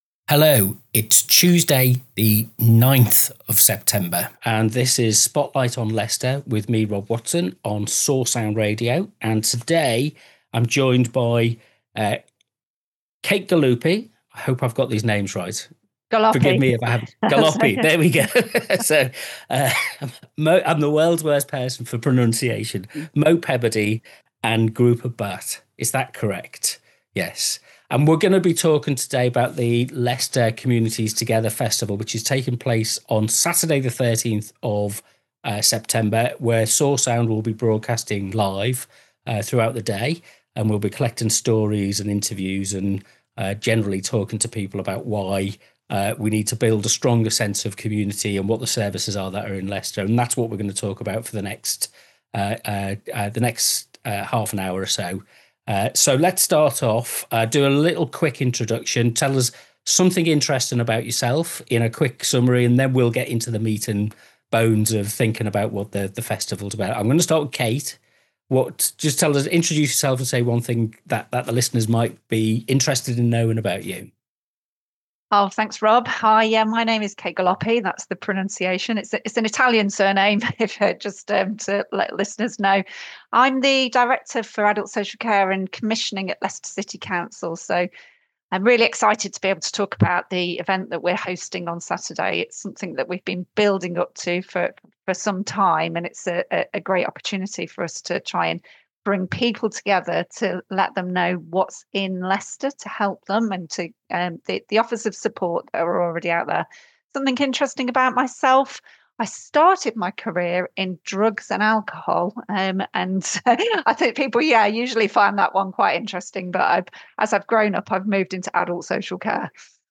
The conversation, recorded as part of Soar Sound’s preparations, brings together three voices with different perspectives on why the event matters and what it offers.